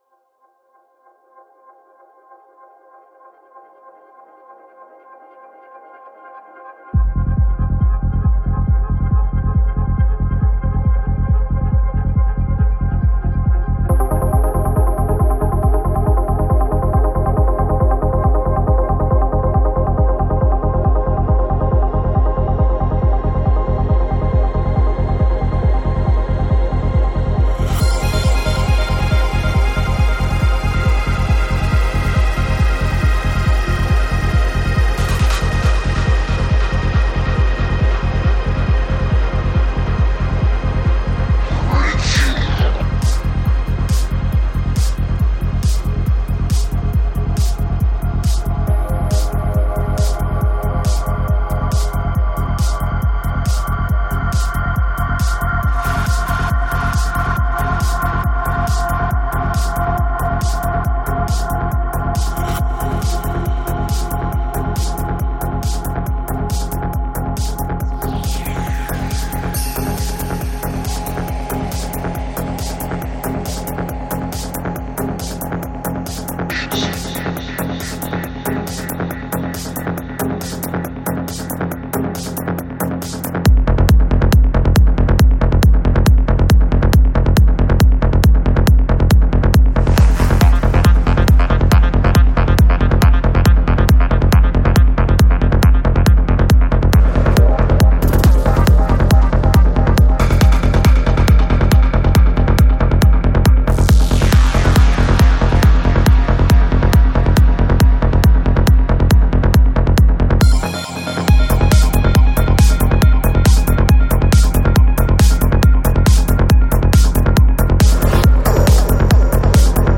Жанр: Psychedelic
Альбом: Psy-Trance